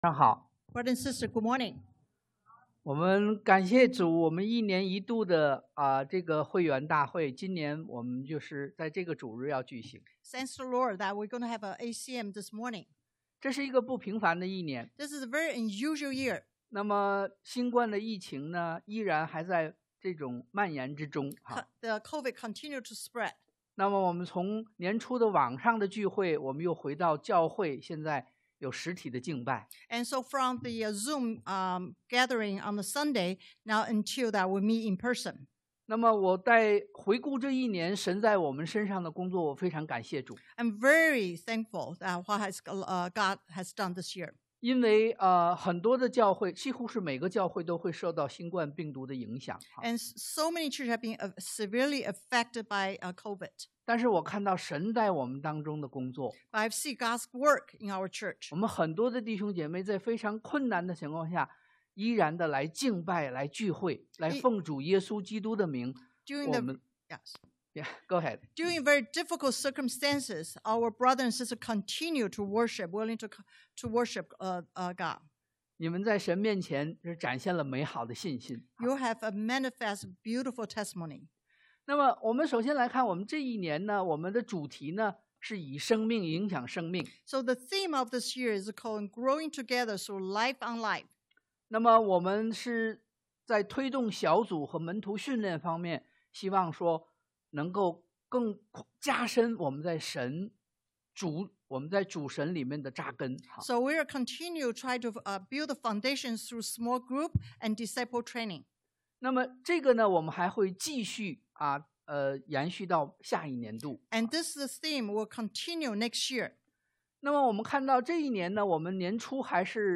ACM 2021 Review and Outlook Presentation 回顧與展望 2. Deacons Presentation 執事年終匯報 3.